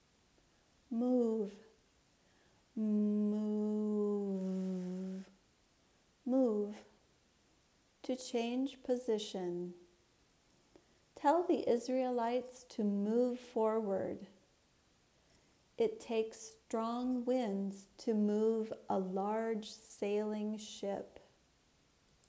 muːv (verb)